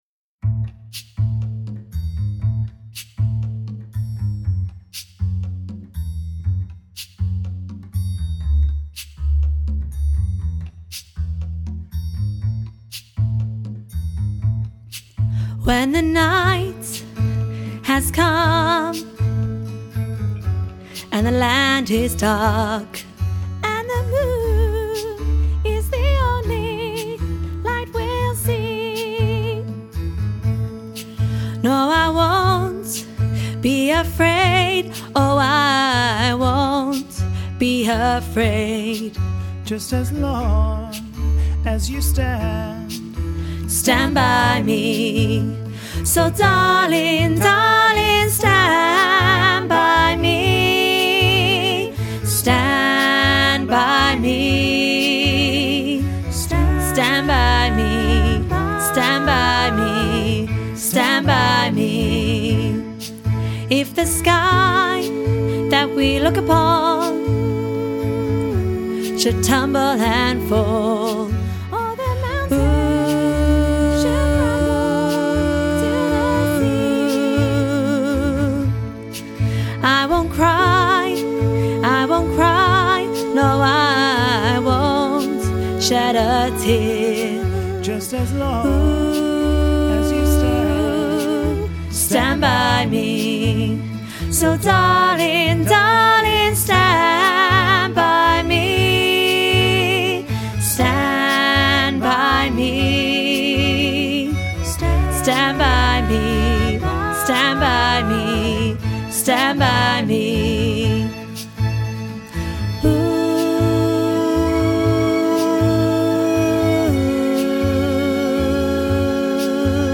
stand-by-me-alto-half-mix.mp3